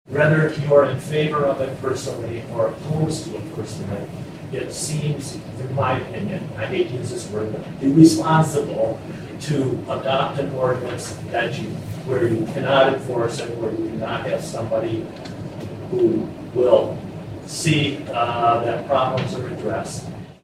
BRONSON, MI (WTVB) – The Bronson City Council voted unanimously during its meeting Monday afternoon to take no further action on a proposed ordinance that would have allowed urban chickens to be kept within the city limits.